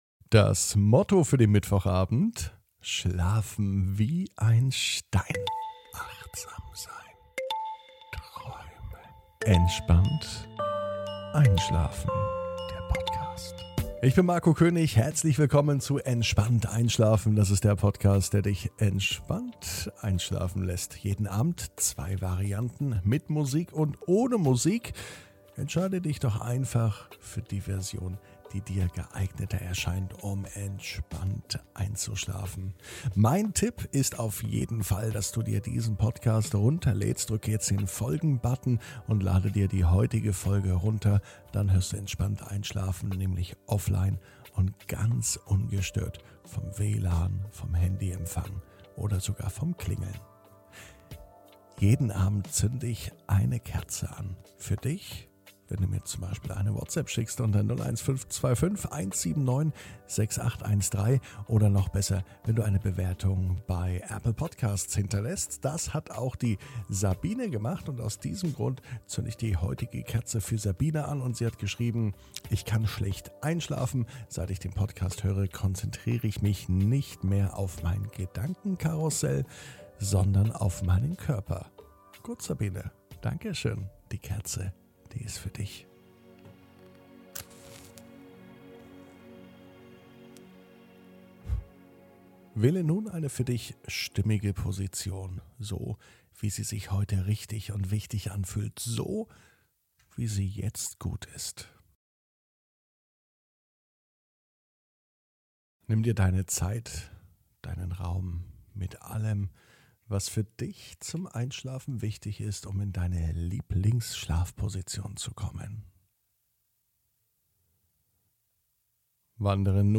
(ohne Musik) Entspannt einschlafen am Mittwoch, 23.06.21 ~ Entspannt einschlafen - Meditation & Achtsamkeit für die Nacht Podcast
Entspannt einschlafen ist die beste Einschlafhilfe um gesund zu schlafen und um gut zu schlafen.